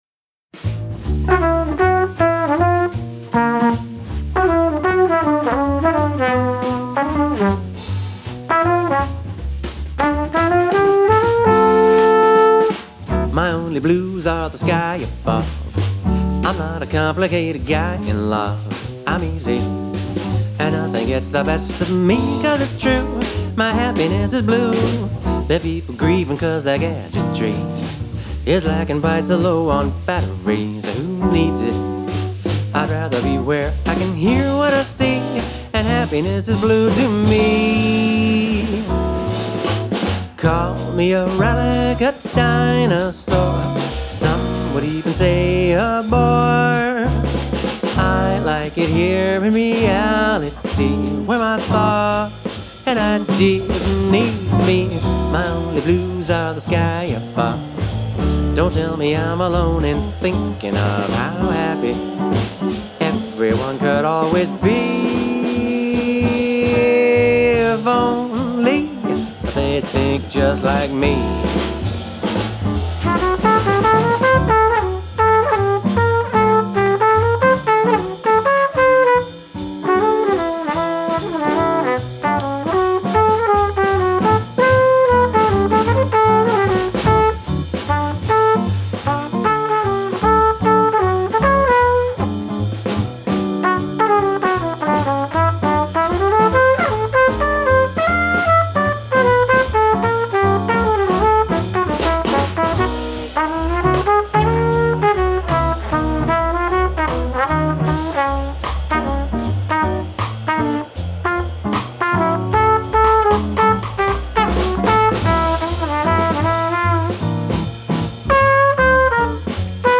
swinging singing (+trumpet)